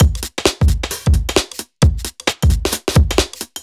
Index of /musicradar/uk-garage-samples/132bpm Lines n Loops/Beats
GA_BeatD132-04.wav